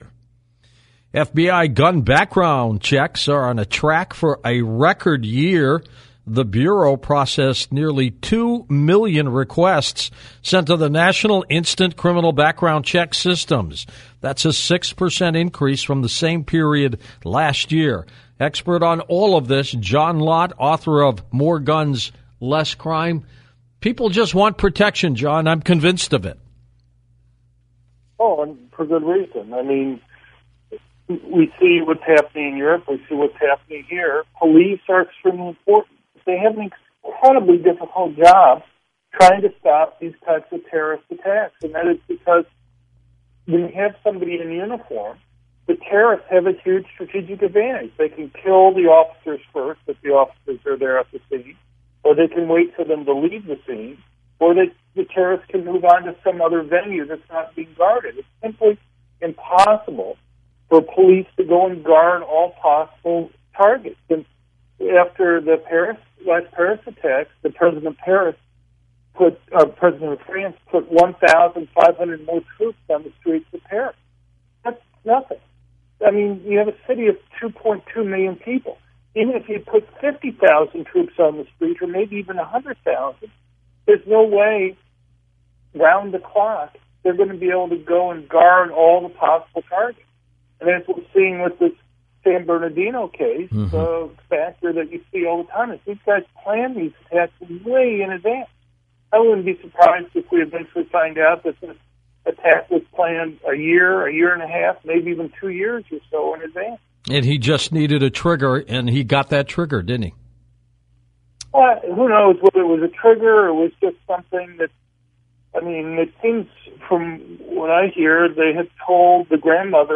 John Lott talked to George Noory about the recent attack in California and the push by President Obama for so-called expanded background checks (Friday, December 5, 2015 from 1:08 to 1:12 AM).